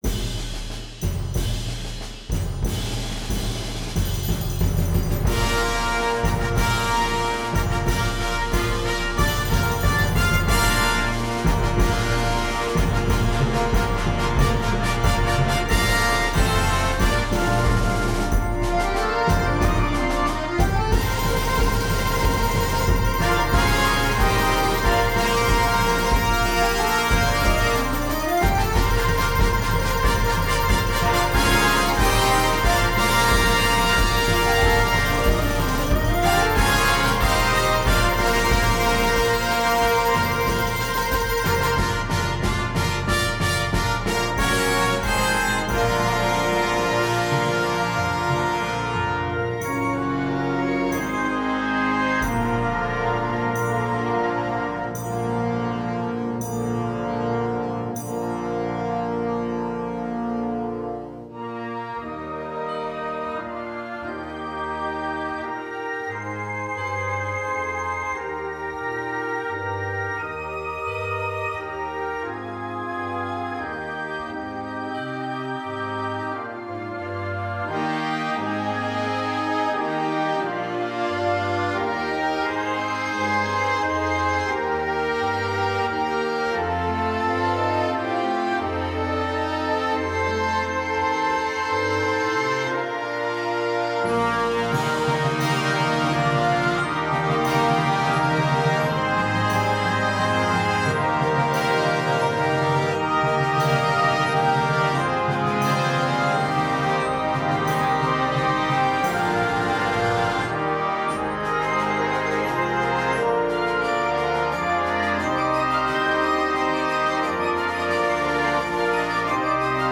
Gattung: Ouvertüre für Blasorchester
Besetzung: Blasorchester